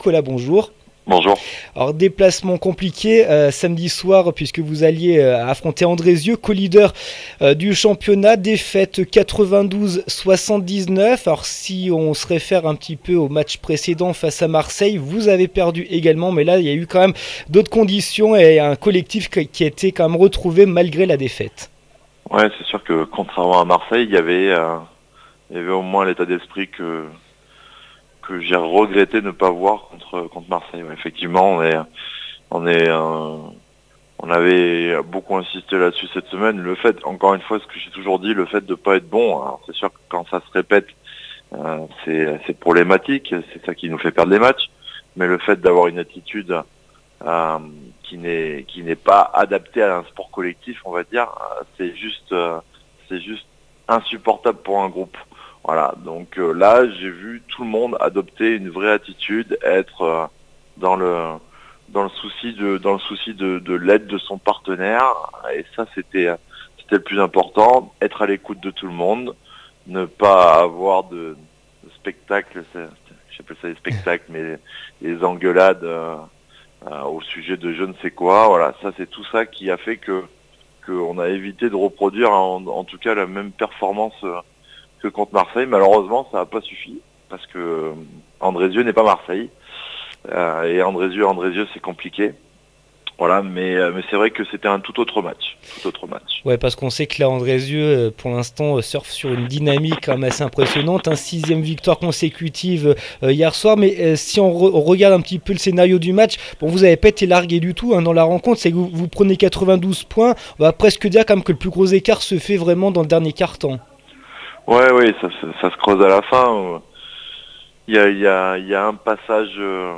9 février 2016   1 - Sport, 1 - Vos interviews, 2 - Infos en Bref   No comments